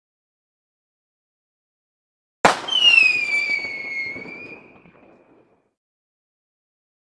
Whistler Rocket
Whistler Rocket.mp3